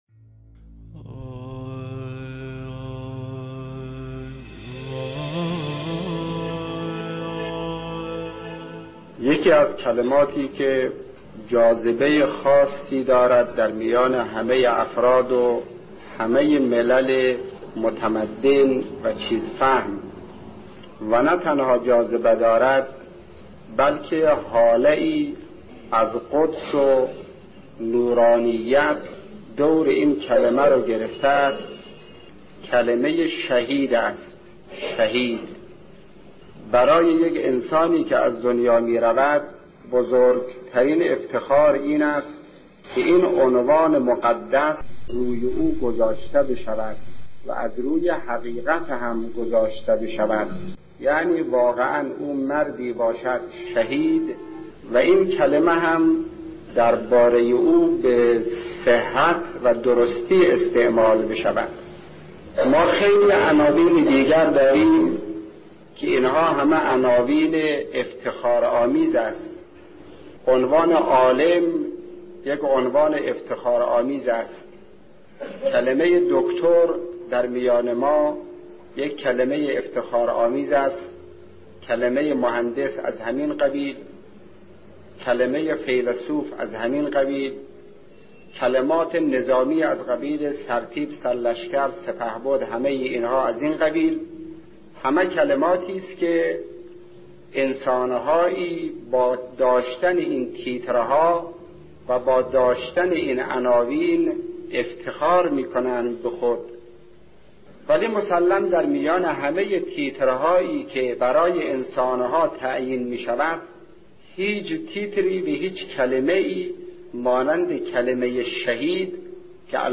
مجموعه پادکست «روایت بندگی» با کلام اساتید بنام اخلاق به کوشش ایکنا گردآوری و تهیه شده است، که نهمین قسمت این مجموعه با کلام آیت‌الله شهید مرتضی مطهری(ره) با عنوان «مقام عزیز شهید» تقدیم مخاطبان گرامی ایکنا می‌شود.